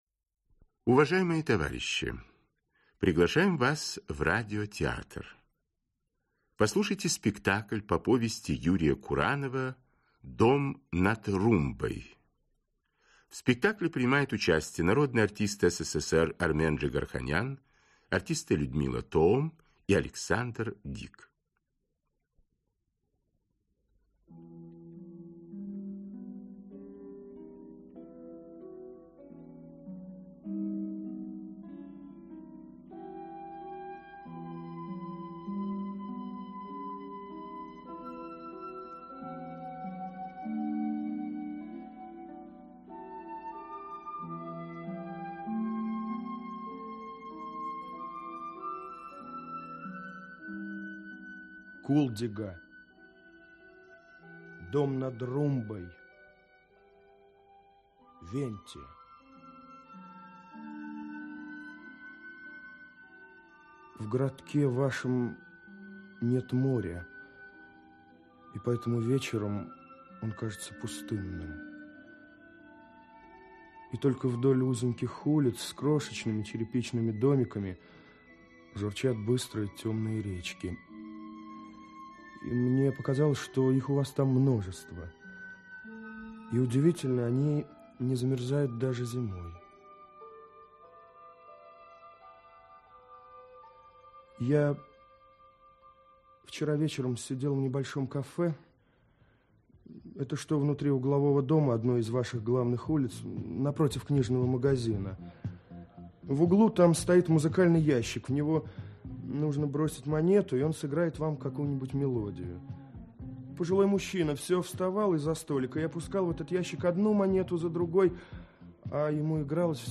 Аудиокнига Дом над Румбой | Библиотека аудиокниг
Aудиокнига Дом над Румбой Автор Юрий Куранов Читает аудиокнигу Актерский коллектив.